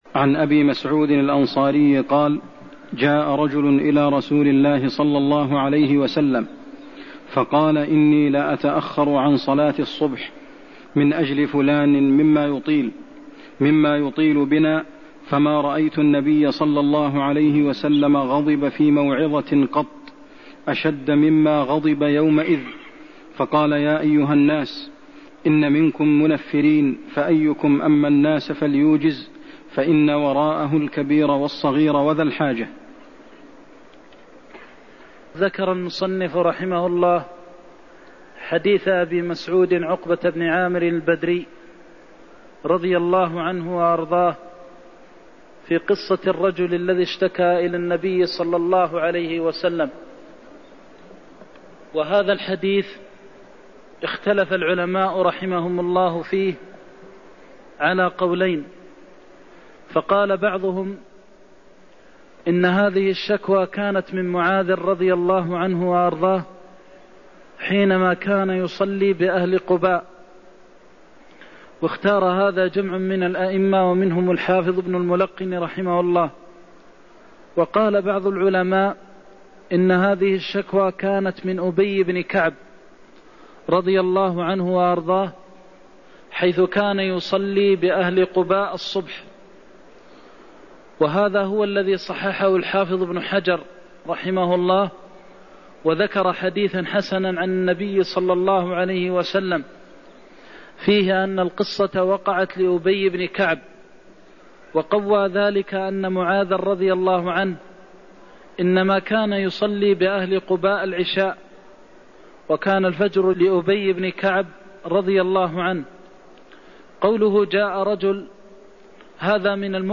المكان: المسجد النبوي الشيخ: فضيلة الشيخ د. محمد بن محمد المختار فضيلة الشيخ د. محمد بن محمد المختار إن منكم منفرين فأيكم أم الناس فليوجز (77)